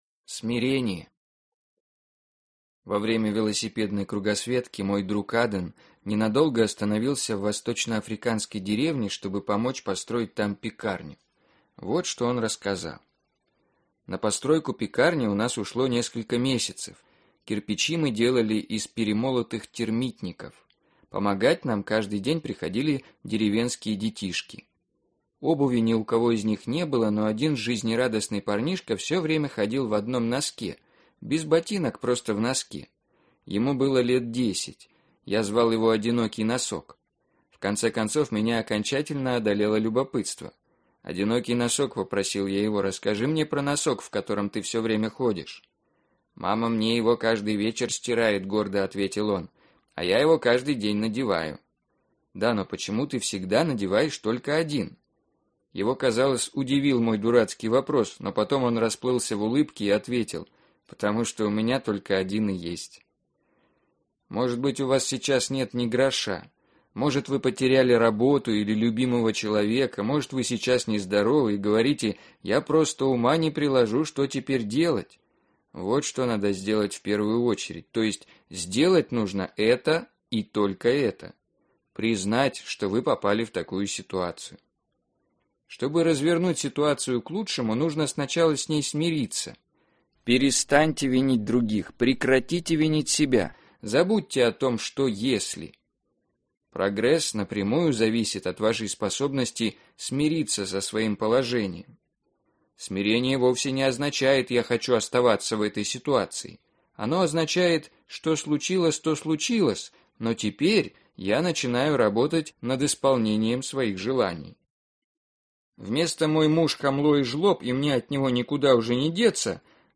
Книга начитана очень хорошо, и передает эмоции.